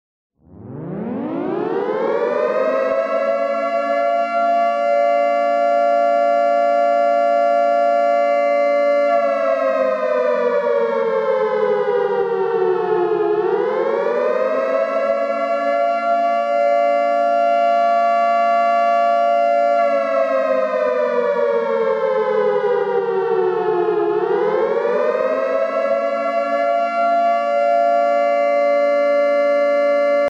Kostenlose Klingeltöne Alarm Sirene